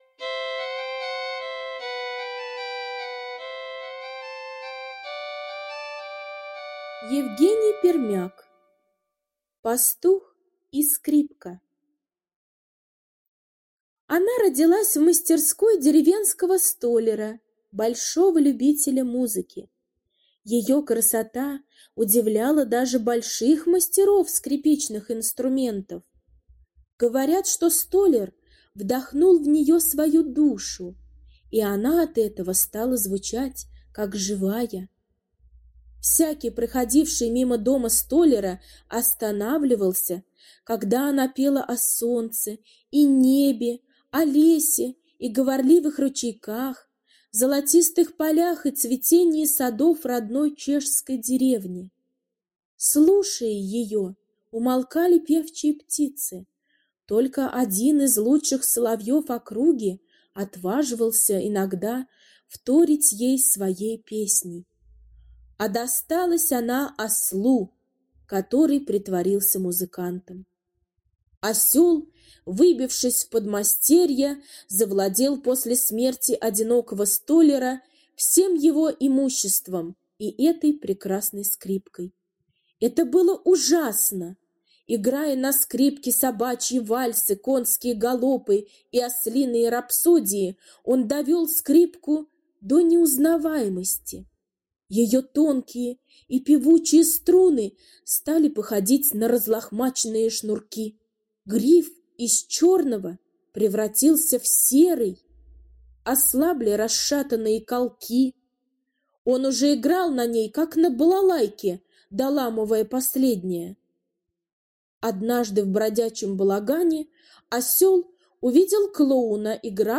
Пастух и скрипка — аудиосказка Пермяка Е. История про чудесную скрипку, в которую столяр вложил всю свою душу, и она звучала как живая...